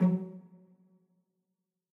pizz.ogg